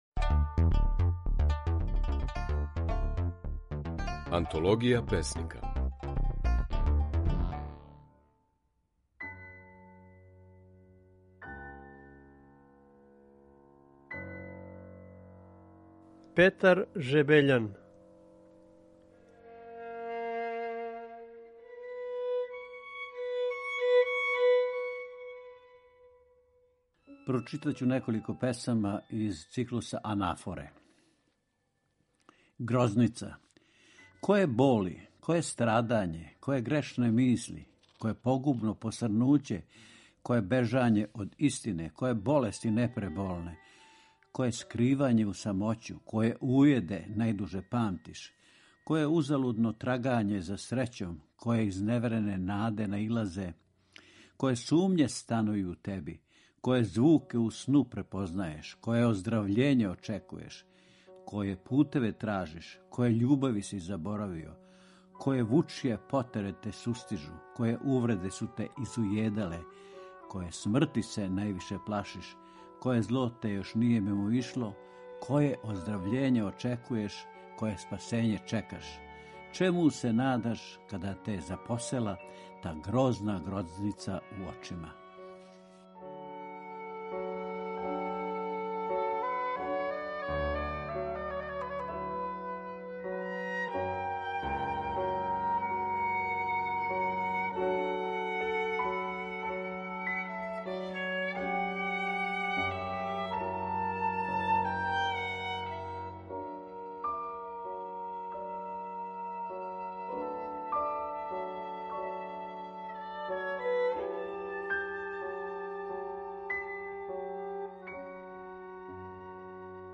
Своје стихове говори песник
Емитујемо снимке на којима своје стихове говоре наши познати песници